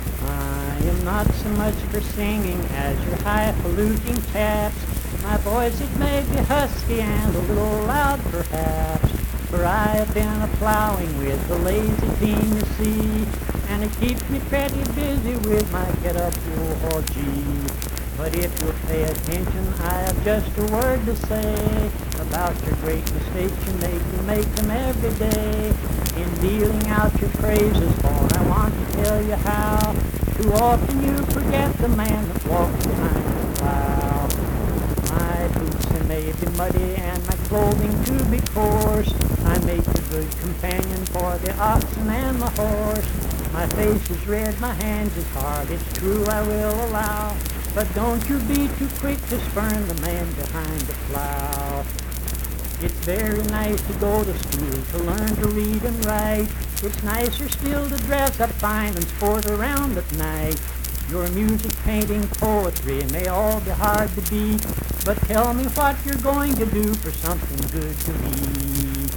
Man Behind The Plow - West Virginia Folk Music | WVU Libraries
Accompanied (guitar) and unaccompanied vocal music
Performed in Mount Harmony, Marion County, WV.
Voice (sung)